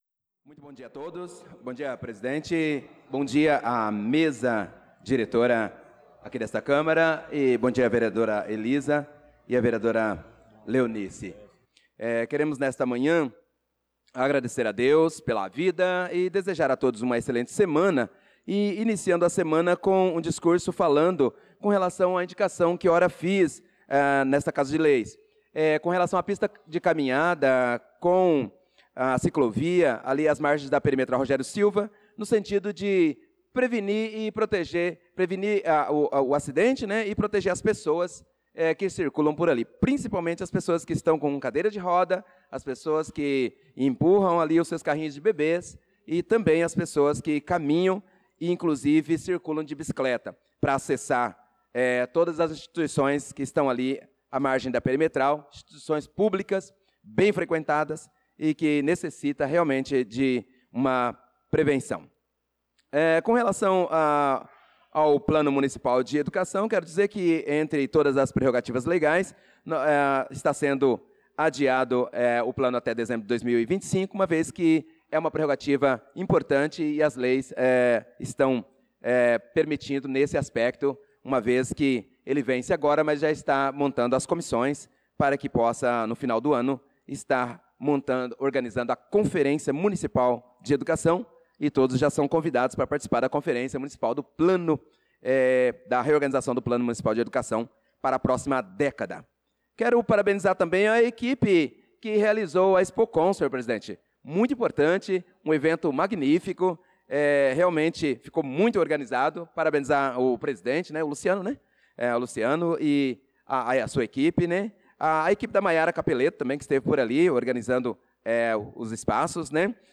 Pronunciamento do vereador Prof: Nilson na Sessão Ordinária do dia 09/06/2025